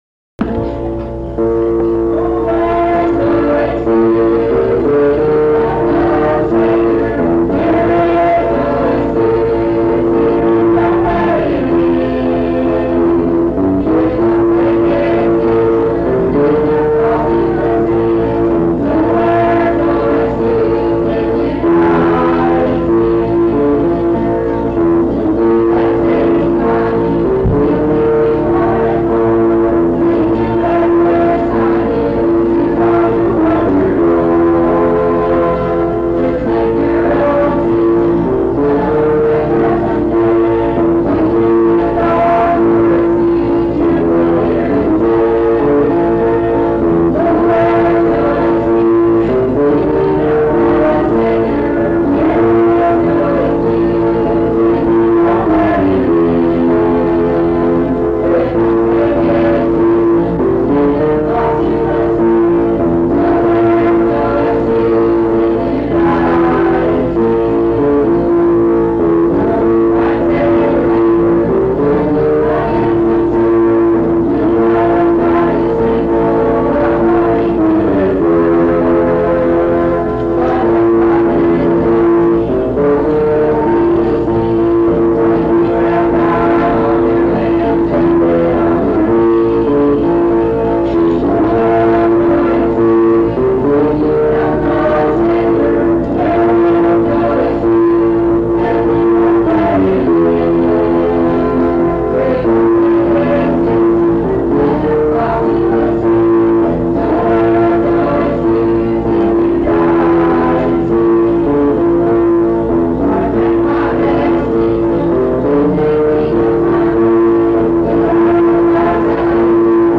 Quartet Number (131I tape 4a).mp3